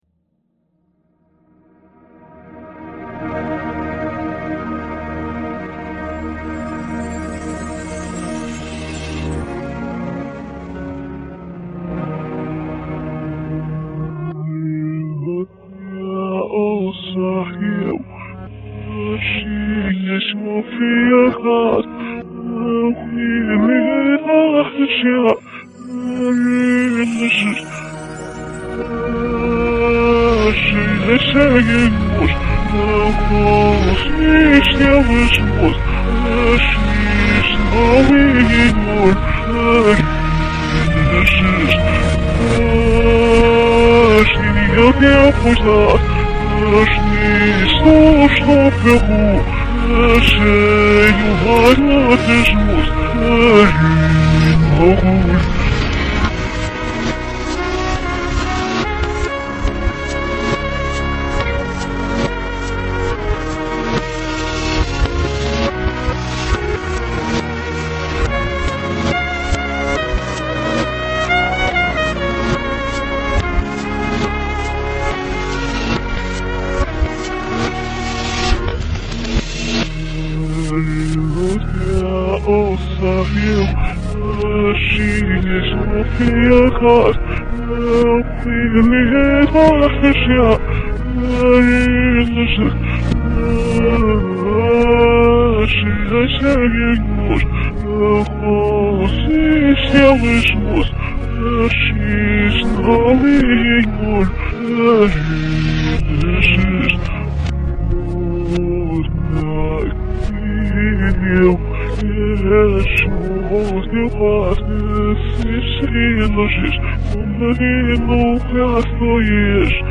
扭曲版：